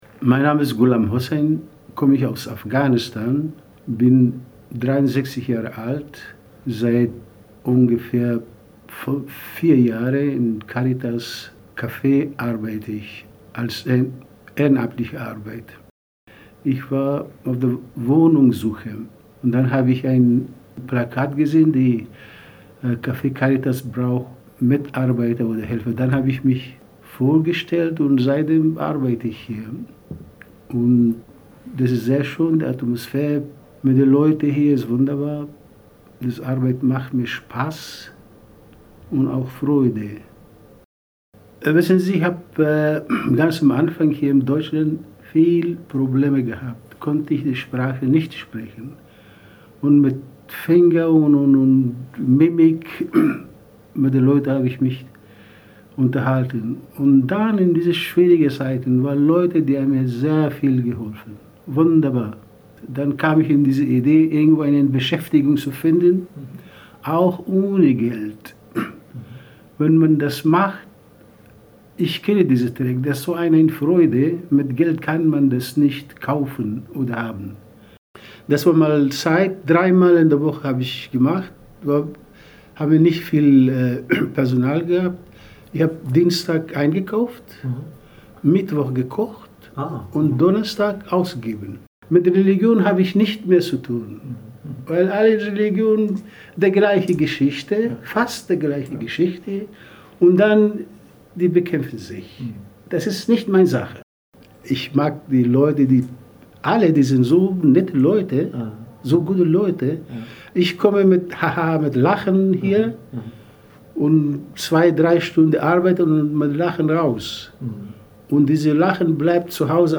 Neben den großformatigen Fotografien können die Ausstellungsbesucher Mitschnitte aus Interviews mit den Porträtierten per QR-Code auf ihrem Smartphone anhören.